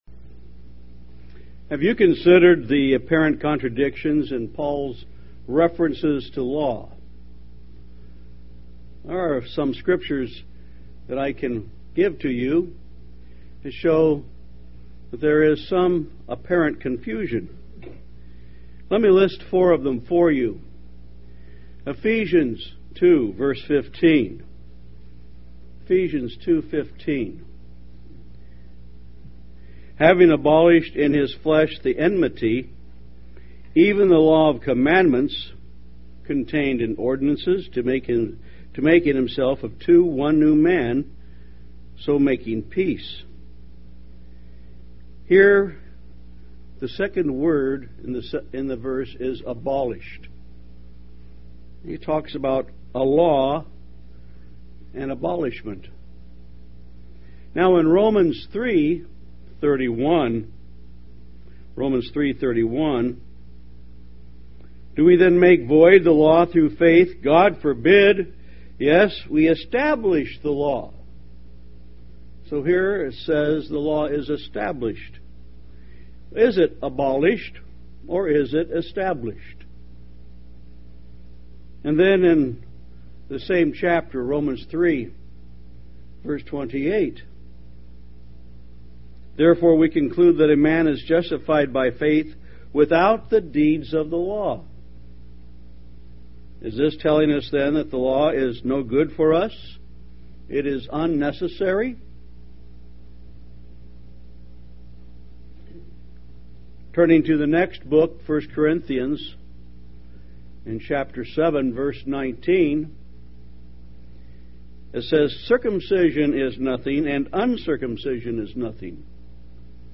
Given in Tampa, FL St. Petersburg, FL
UCG Sermon Studying the bible?